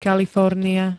Kalifornia [-n-] -ie ž.
Zvukové nahrávky niektorých slov